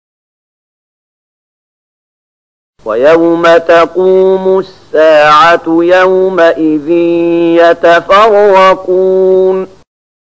030014 Surat Ar-Ruum ayat 14 dengan bacaan murattal ayat oleh Syaikh Mahmud Khalilil Hushariy: